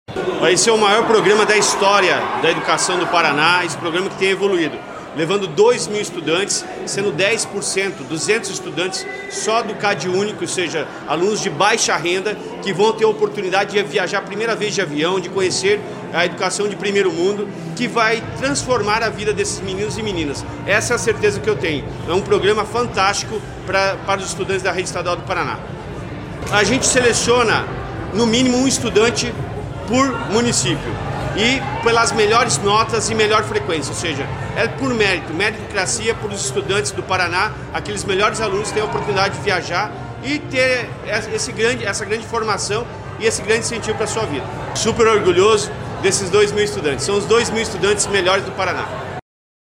Sonora do secretário da Educação, Roni Miranda, sobre a edição de 2026 do programa Ganhando o Mundo